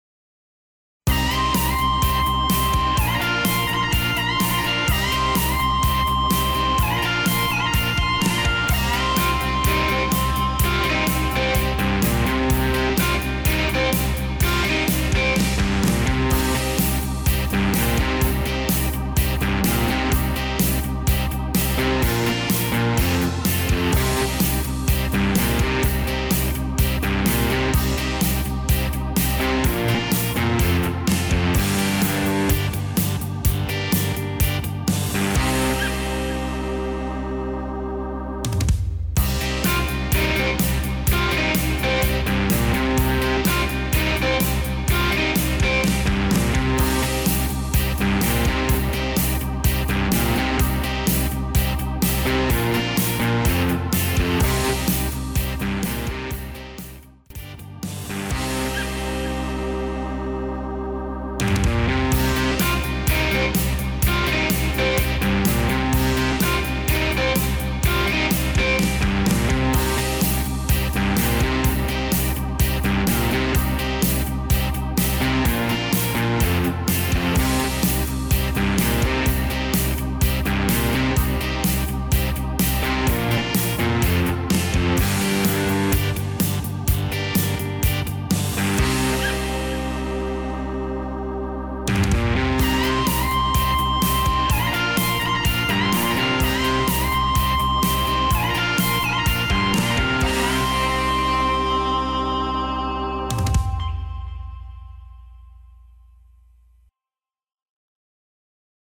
Rhythm Track